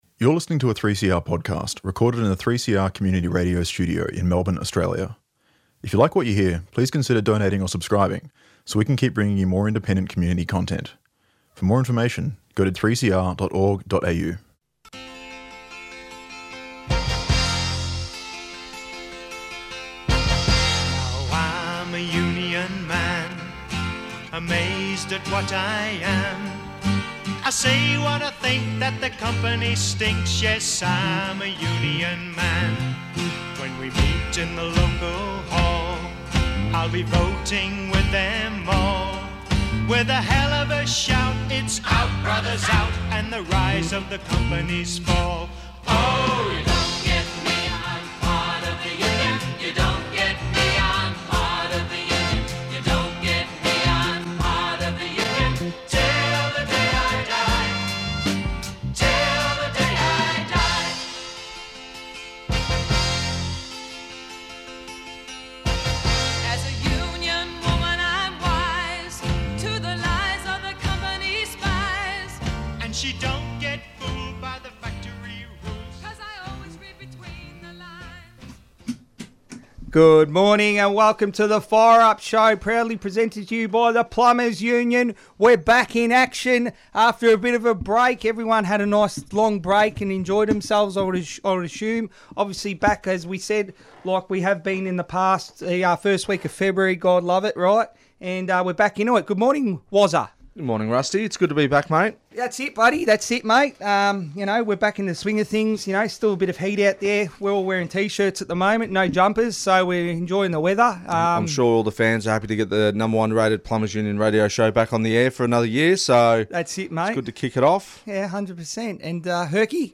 Upcoming events: Next Gen meeting (Tue 17 Feb): PICAC Brunswick 4.30pm Protected Action (Mon 9 Feb): Austin Hospital members' 24h stoppage Tweet Fire Up Thursday 6:30am to 7:00am The Plumbers Union weekly radio show.